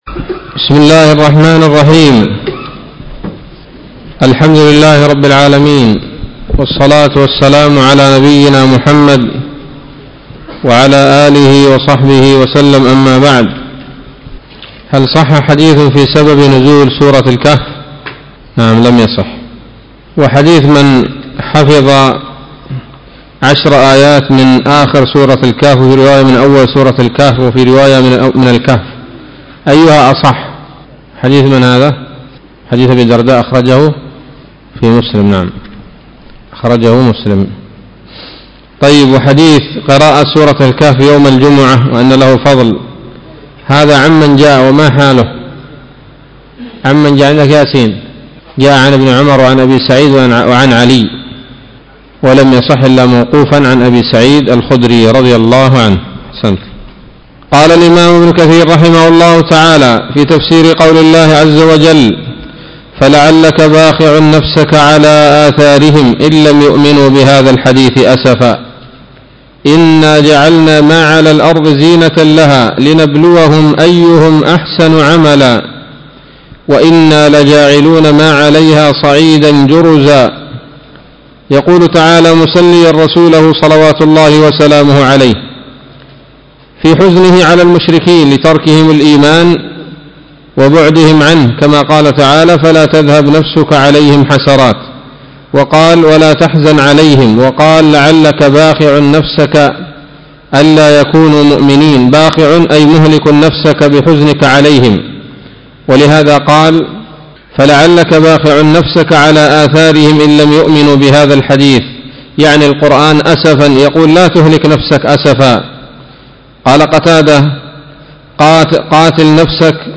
الدرس الثاني من سورة الكهف من تفسير ابن كثير رحمه الله تعالى